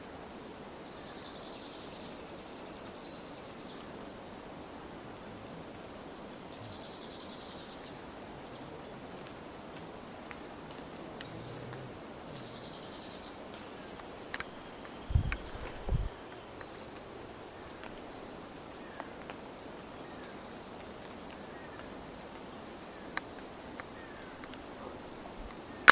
Mosqueta Media Luna (Pogonotriccus eximius)
Nombre en inglés: Southern Bristle Tyrant
Fase de la vida: Adulto
Localidad o área protegida: Parque Provincial Esmeralda
Condición: Silvestre
Certeza: Observada, Vocalización Grabada